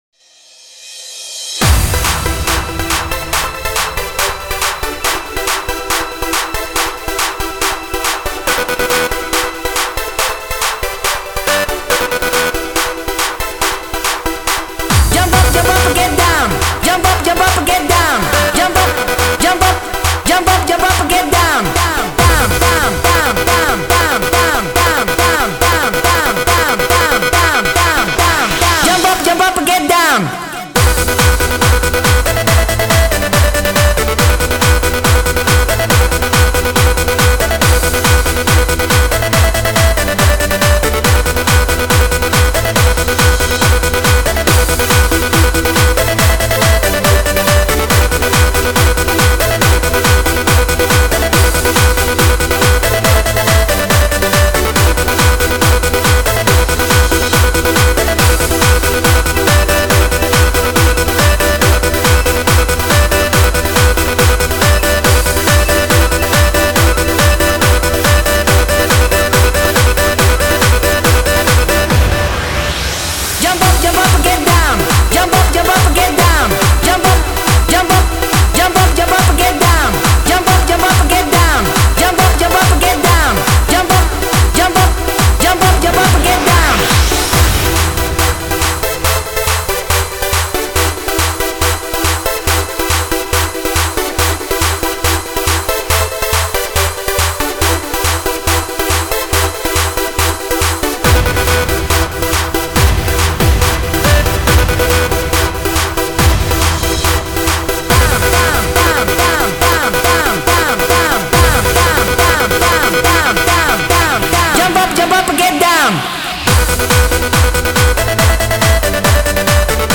Назад в ¤Techno Dance¤